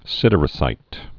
(sĭdər-ə-sīt)